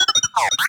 beeps3.ogg